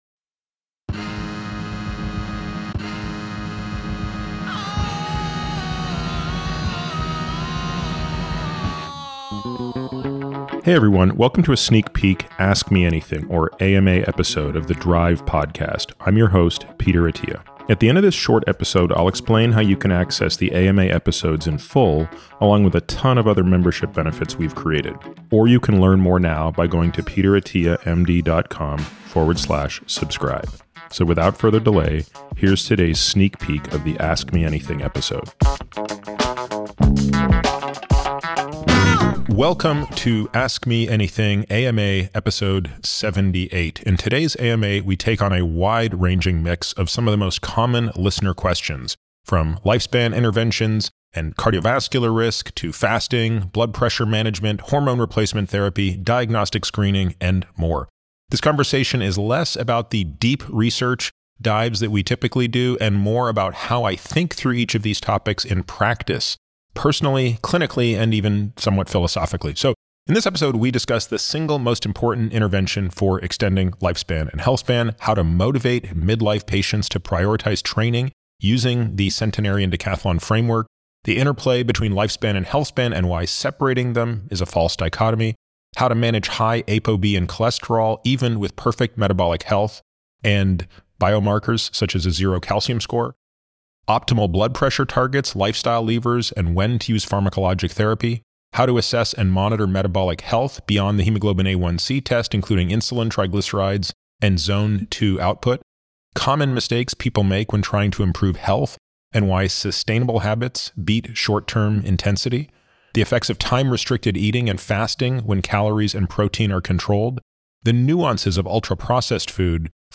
In this “Ask Me Anything” (AMA) episode, Peter tackles a wide-ranging set of listener questions spanning lifespan interventions, exercise, cardiovascular risk reduction, time-restricted eating, blood pressure management, hormone therapy, diagnostics, and more. Peter reveals the single most important lever for extending healthspan and lifespan, and explains how he motivates midlife patients using the Centenarian Decathlon framework.